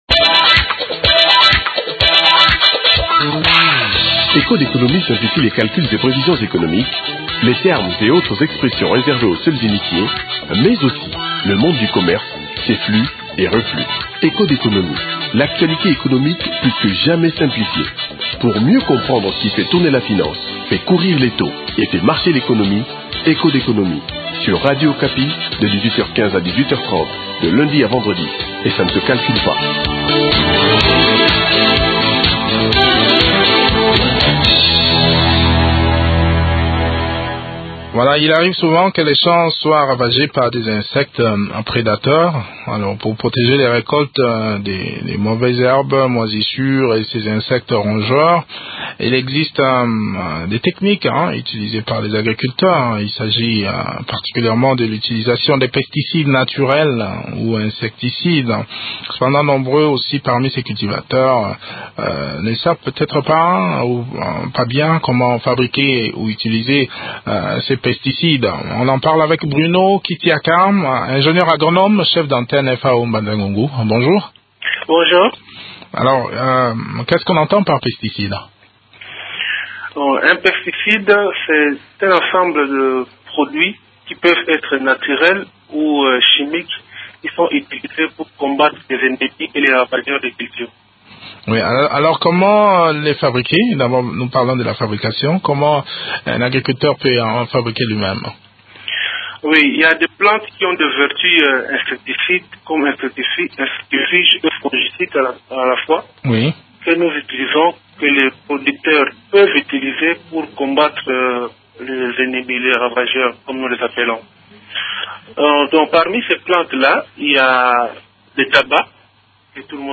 Eléments de réponse dans cet entretien que